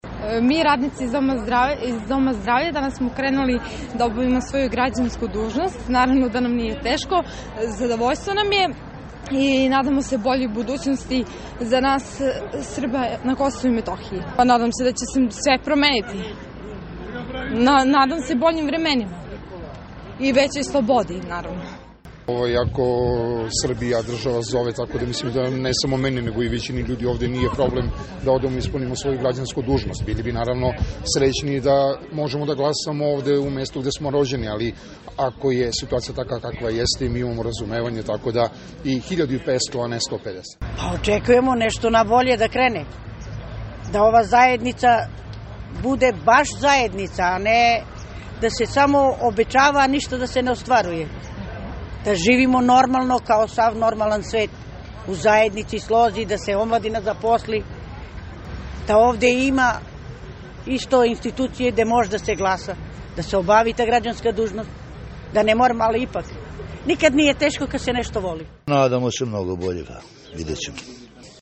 Anketa iz Gračanice